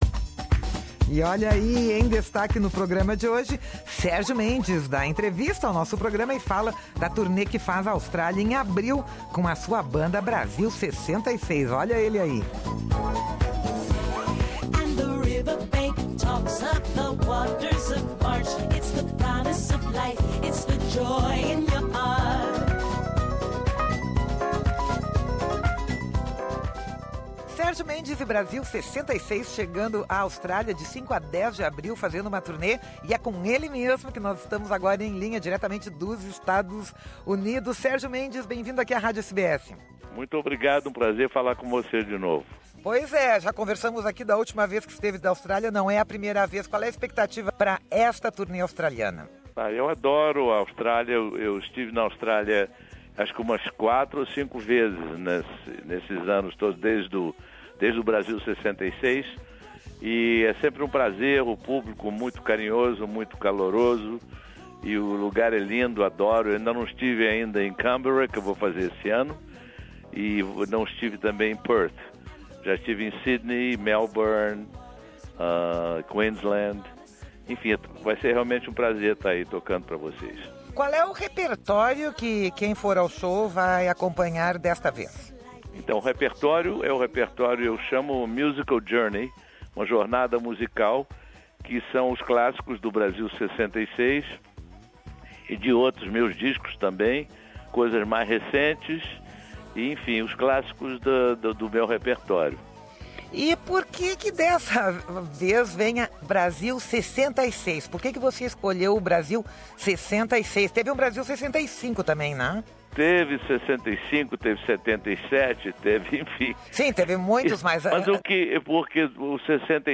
Sérgio Mendes dá entrevista ao nosso programa e fala da turnê que faz à Austrália, de 5 a 10 de abril, com a sua banda "Brasil' 66".
Ao final da entrevista, tocamos um pouco de Nanã Imborô para quem quiser conferir.